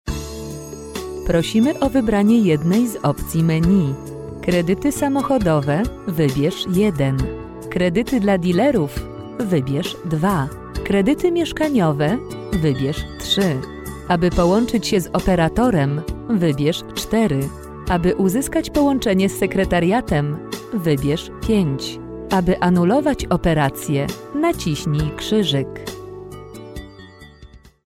Female 30-50 lat
Nagranie lektorskie